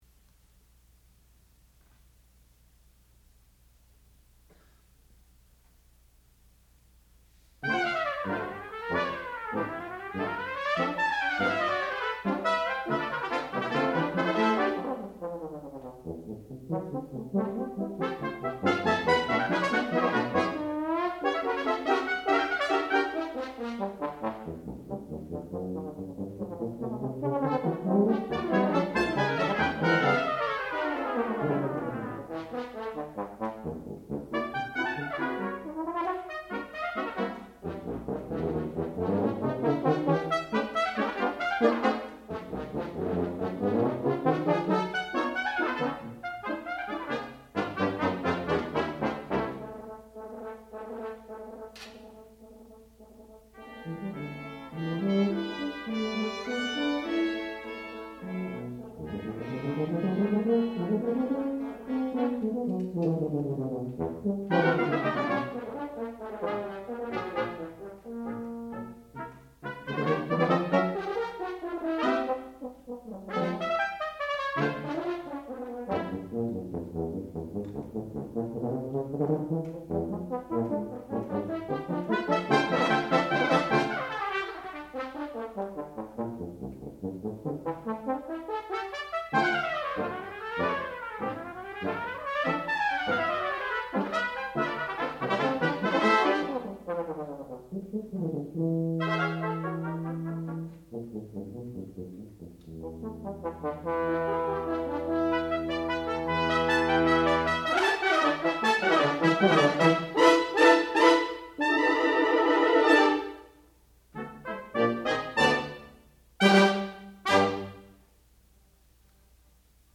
sound recording-musical
classical music
trumpet
horn